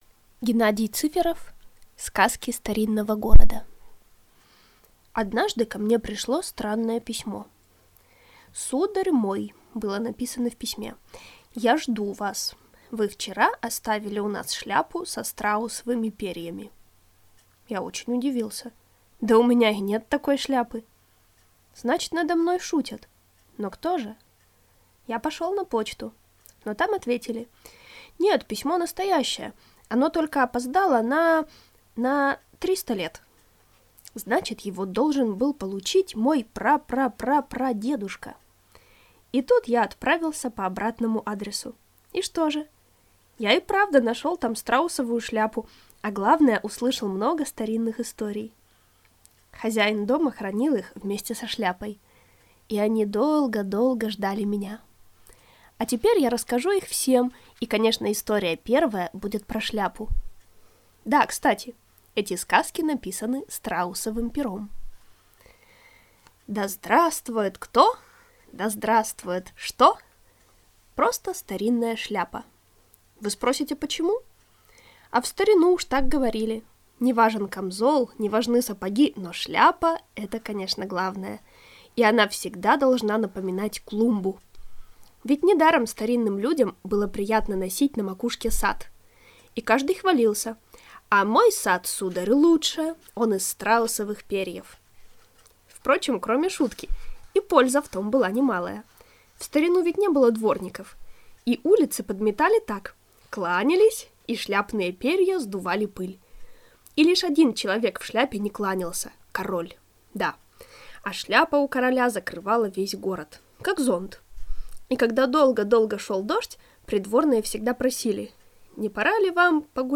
Сказки старинного города - аудиосказка Геннадия Цыферова - слушать онлайн